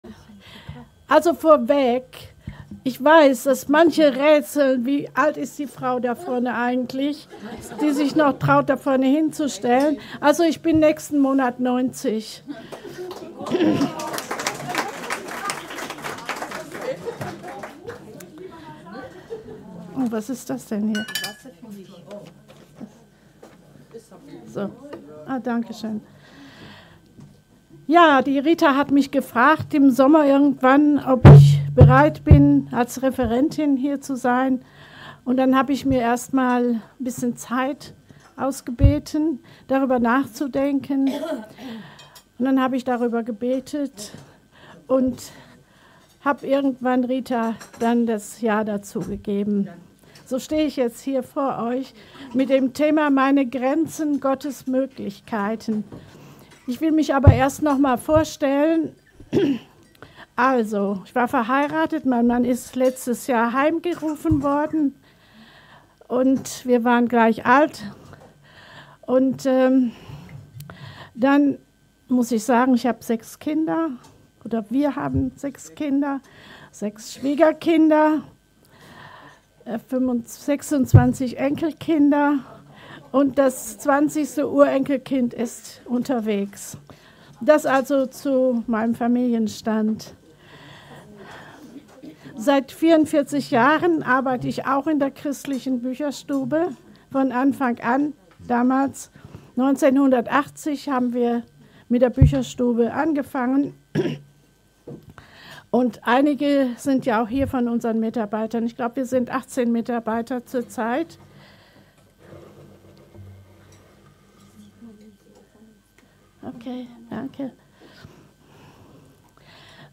Meine Grenzen und Gottes Möglichkeiten - Vortrag Frauenfrühstück
meine-grenzen-und-gottes-moeglichkeiten-vortrag-frauenfruehstueck.mp3